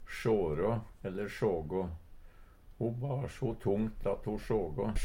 Høyr på uttala Ordklasse: Verb Kategori: Karakteristikk Attende til søk